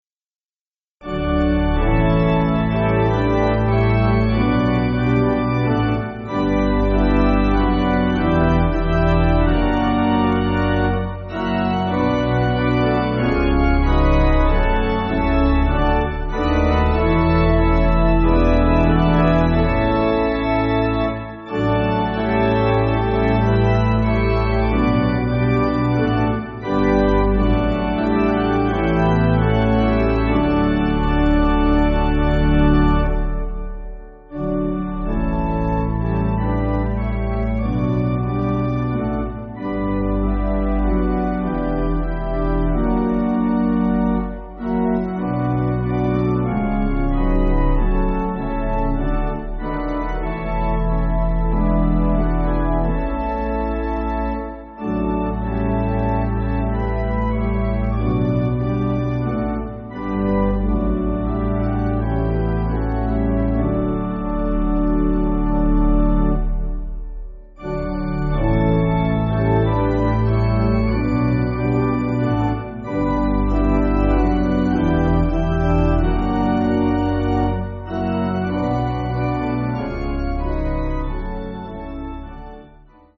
(CM)   8/G